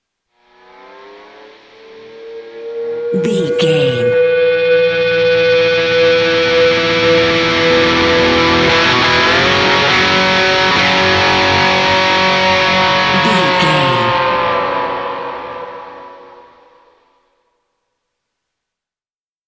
Epic / Action
Ionian/Major
electric guitar
Slide Guitar